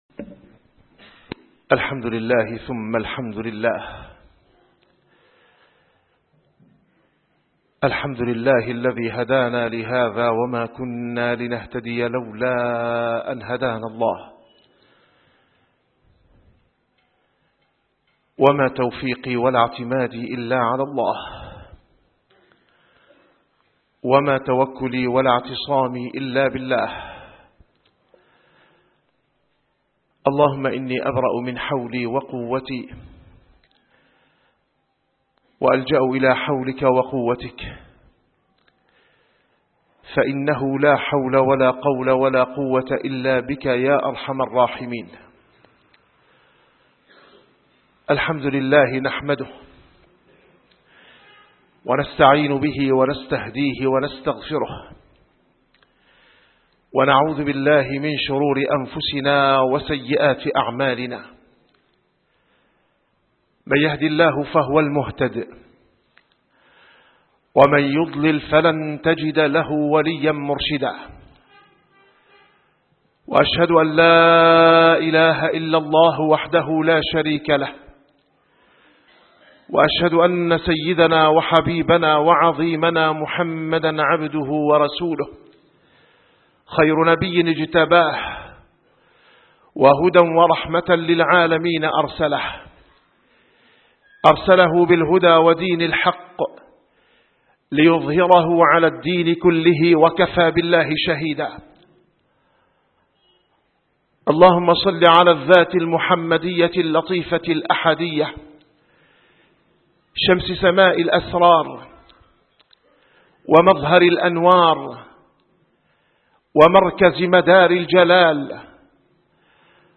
- الخطب - الهجرة النبوية (أثرها المسلكي في حياة المسلم )